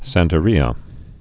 San·te·ri·a also san·te·ri·a
(săntə-rēə, sän-)